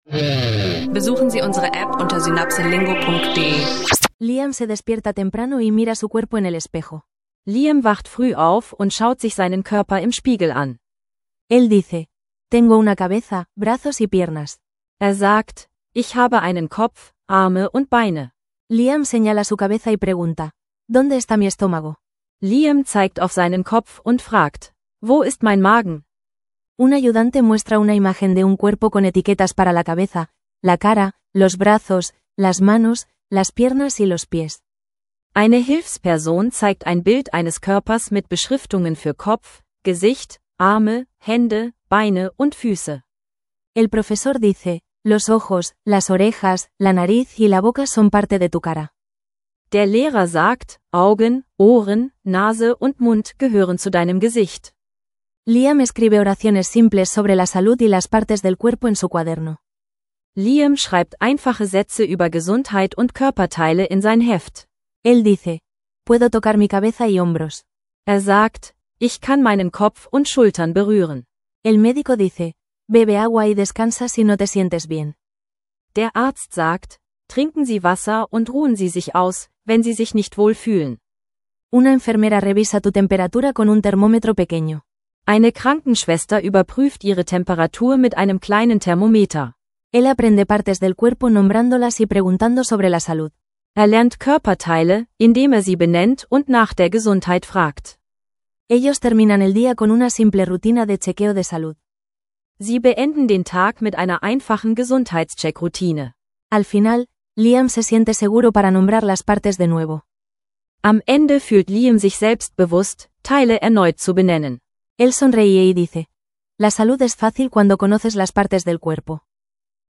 Lerne Körperteile, Gesundheit und gesellschaftliche Themen auf Spanisch – praxisnaher Sprachkurs im Podcastformat.